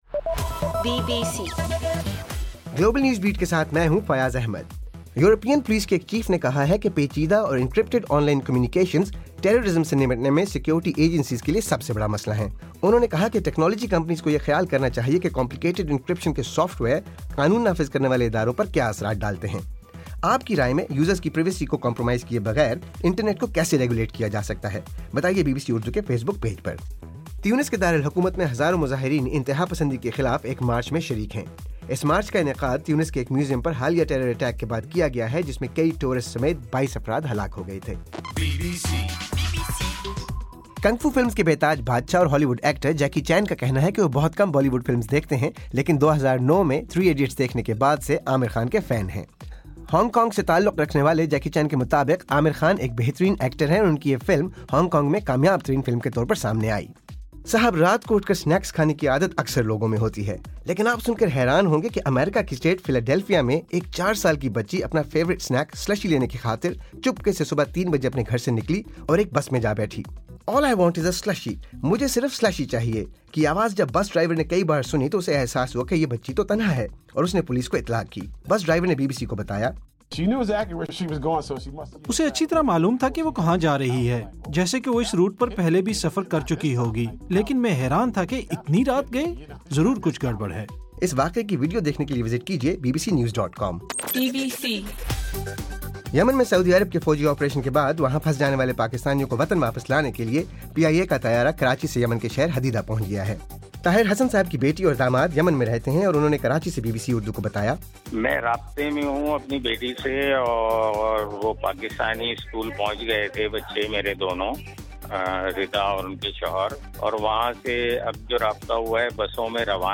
مارچ 29: رات 10 بجے کا گلوبل نیوز بیٹ بُلیٹن